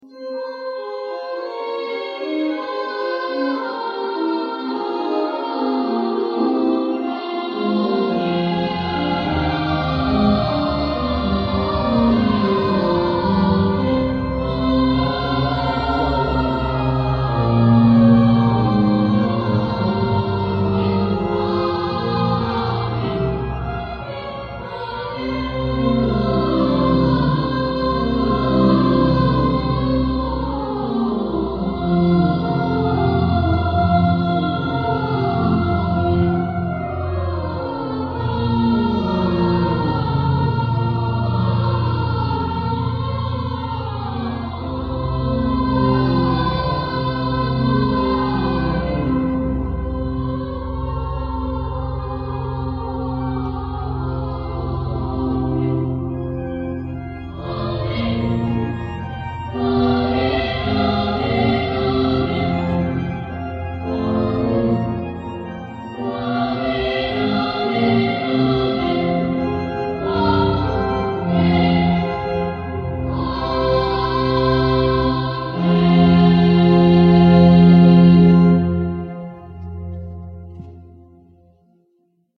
realizó una grabación analógica
de cinco cantos de la escolanía
al órgano de la basílica.